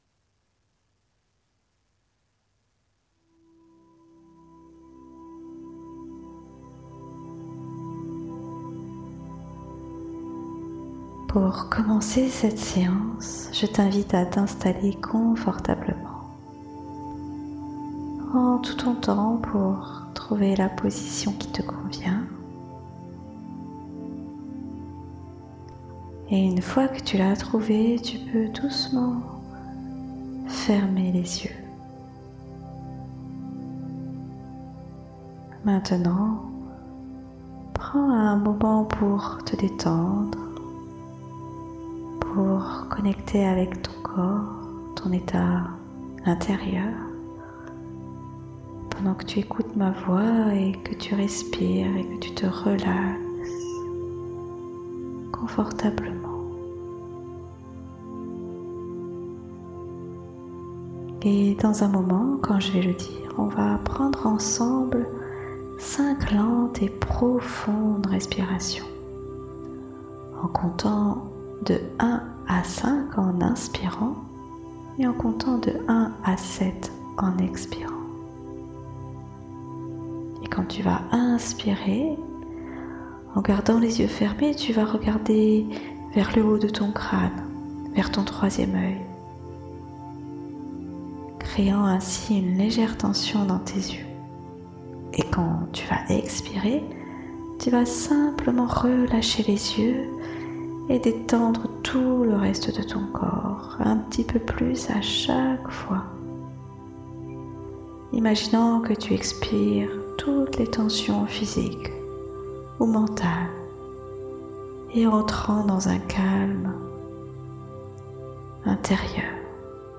Pour vous familiariser avec mon approche et créer un espace intérieur propice, je vous offre cette séance audio d'hypnose guidée.